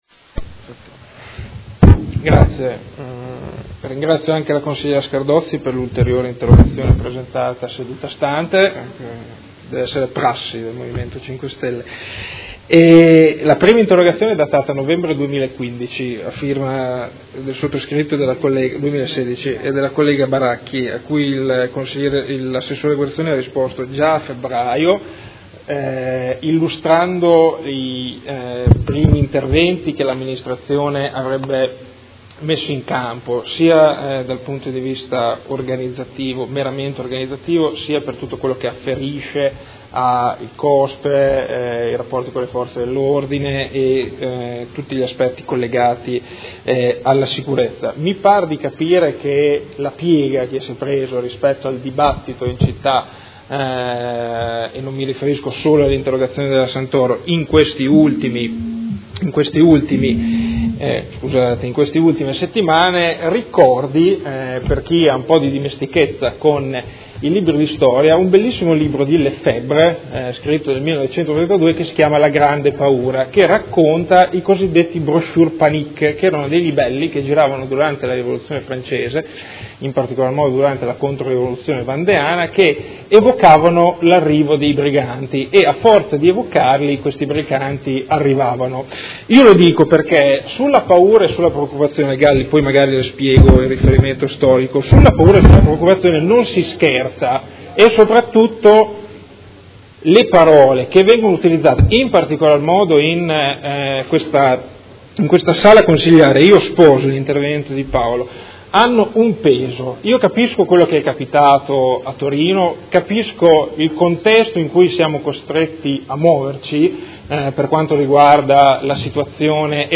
Seduta del 15/06/2017. Dibattito su interrogazione della Consigliera Santoro (Idea-PL) avente per oggetto: Concerto di Vasco Rossi: come verrà garantita la sicurezza?